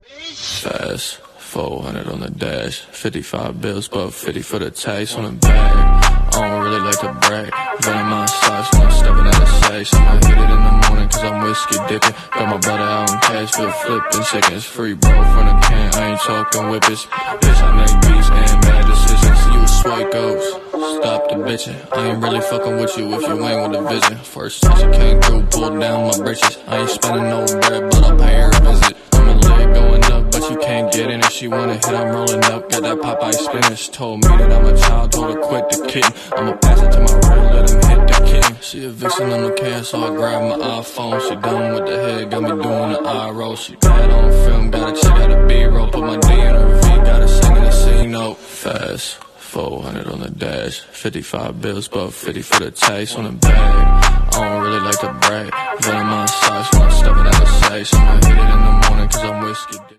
‘GLA 45 AMG in for a full Armytrix turbo back exhaust system’ turn the sound up!